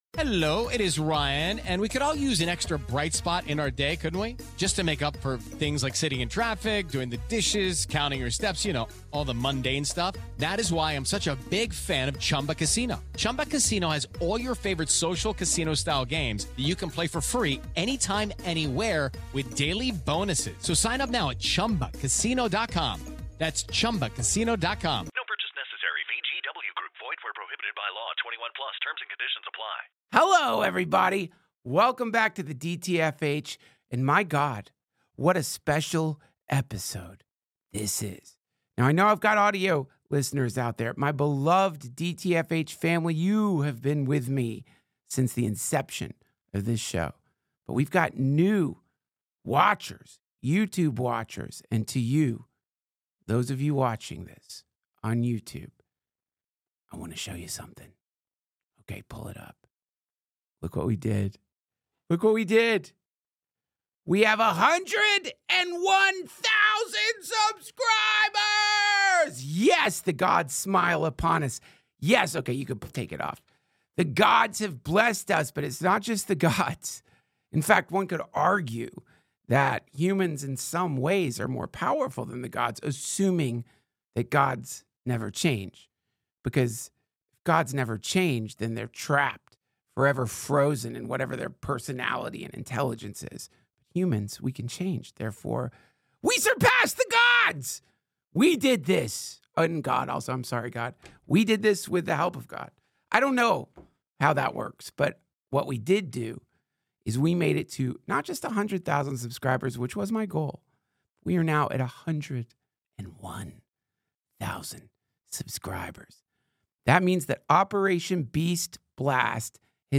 Duncan addresses our new blessed watchers in the darkness, all 100,000 of them! Operation BeastBlast continues apace, we are now on the precipice of phase 2 (of 3148).